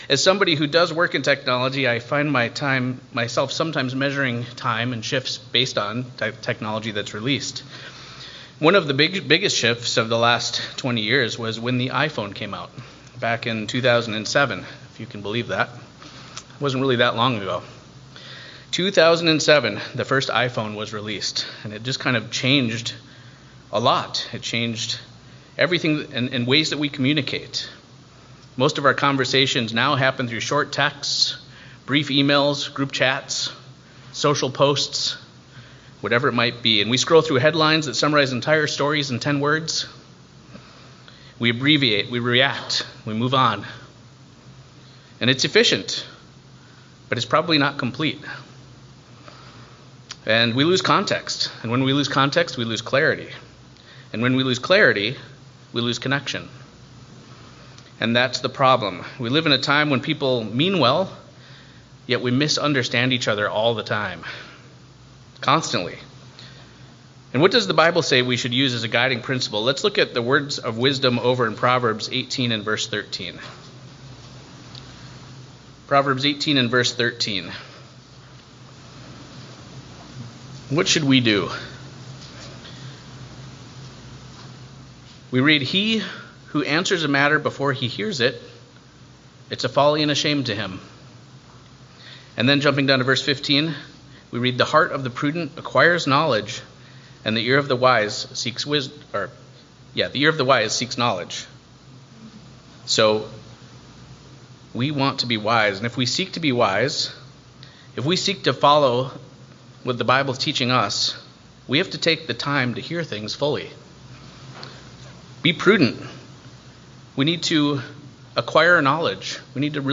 In this thoughtful message, we explore why context matters so deeply in our communication, our relationships, and our understanding of God’s Word.